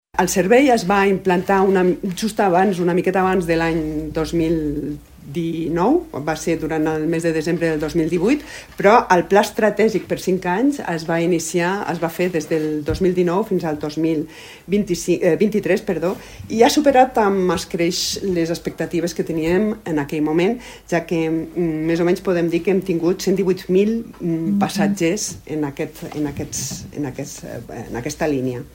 Durant la presentació, la cònsol major Maria del Mar Coma ha recordat que el transport públic parroquial es va posar en funcionament el desembre del 2018 per fomentar l’ús del bus entre la població.